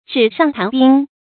注音：ㄓㄧˇ ㄕㄤˋ ㄊㄢˊ ㄅㄧㄥ
紙上談兵的讀法